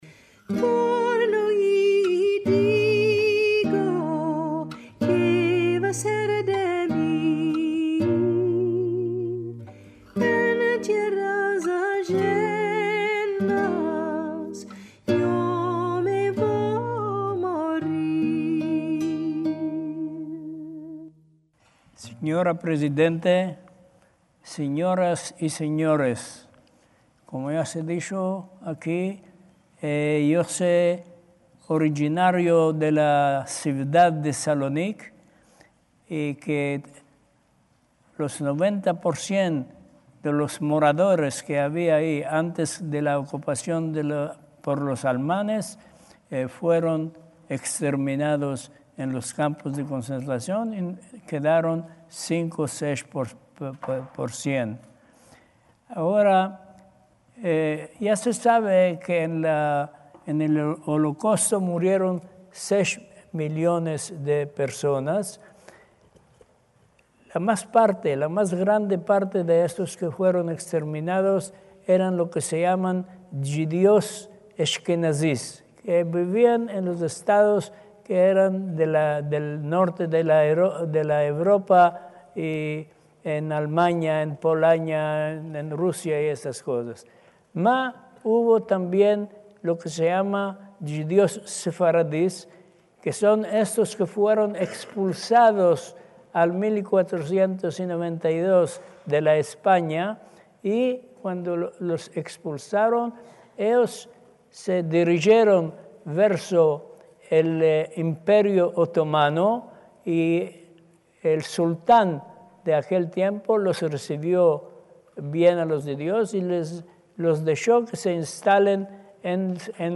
sus palabras en judeoespañol pronunciadas en el acto en recuerdo del holocausto celebrado en la Asamblea de Madrid en enero de 2016